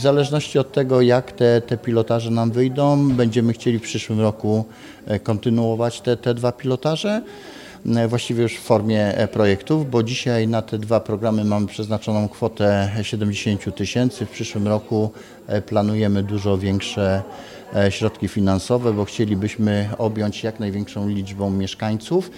Seniorom oferujemy walizkę telemedyczną, czyli kompleksowe rozwiązanie do przeprowadzenia szybkich badań w terenie, kobietom w zaawansowanej ciąży zdalne, całodobowe KTG z monitoringiem aż do dnia porodu! – zaprasza Roman Potocki, starosta powiatu wrocławskiego.